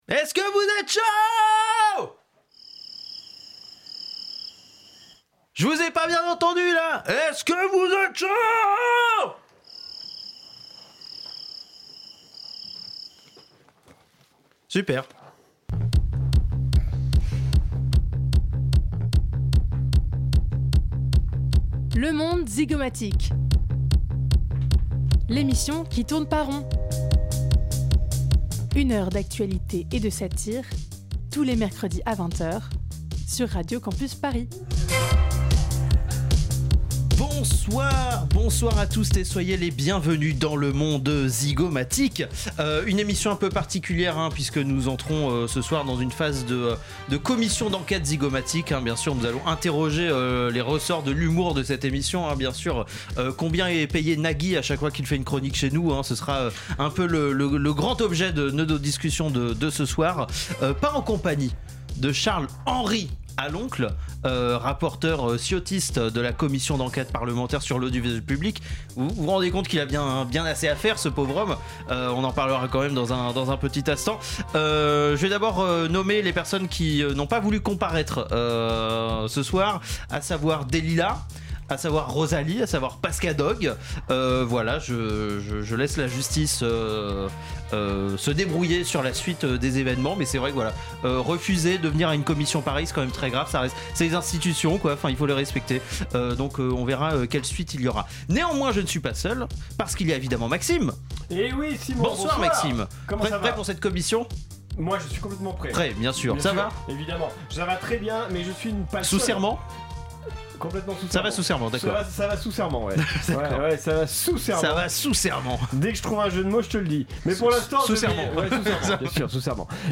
Ceci est une commission d'enquête zygomatique. Toutes les blagues déclamées dans cette émission ont été dites sous serment.